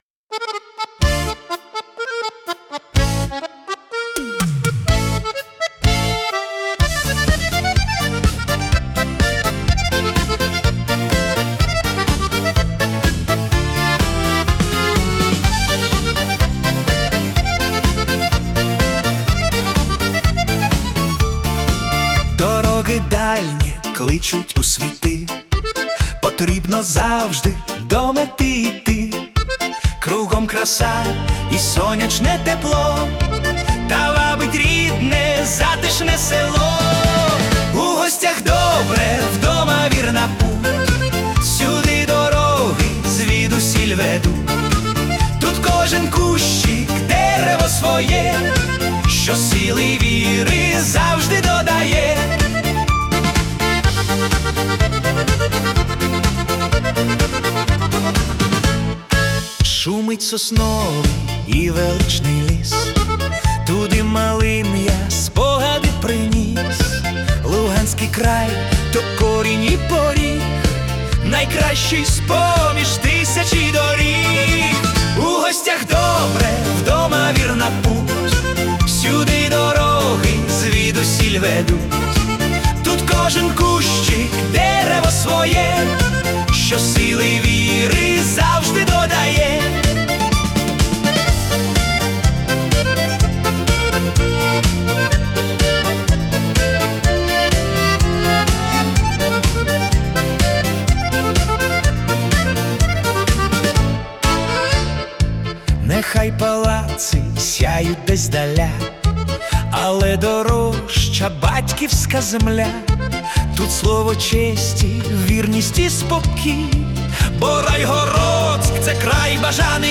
Українська полька / Фолк-поп